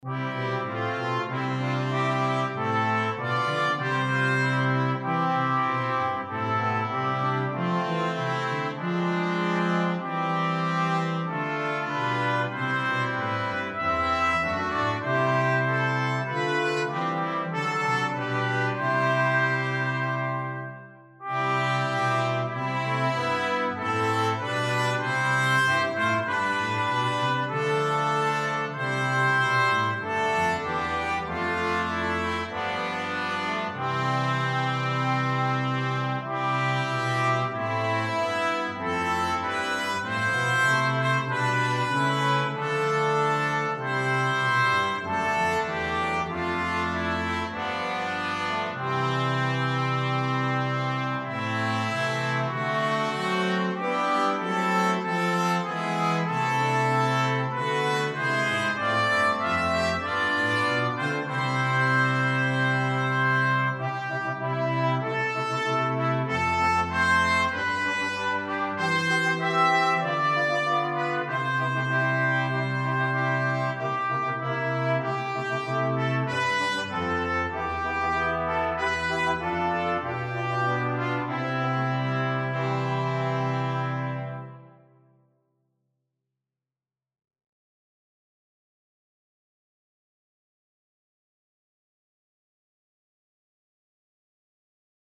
Koperkwintet – mp3